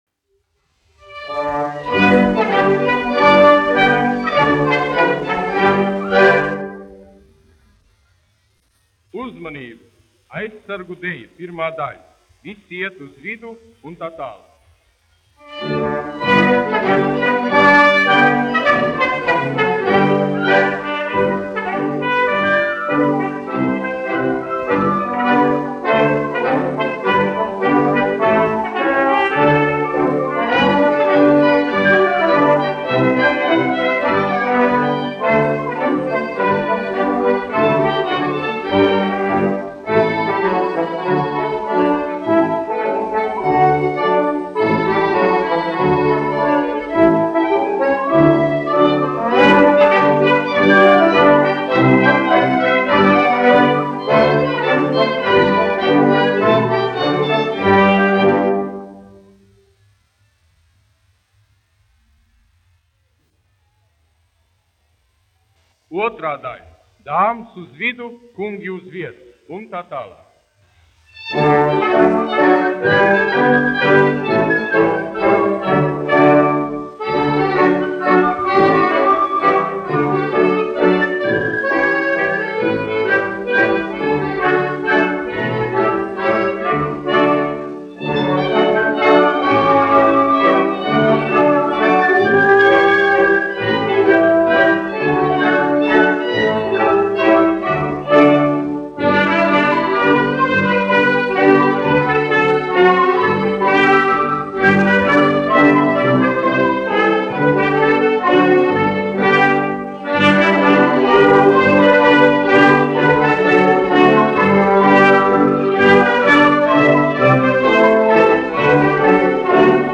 1 skpl. : analogs, 78 apgr/min, mono ; 25 cm
Deju mūzika
Skaņuplate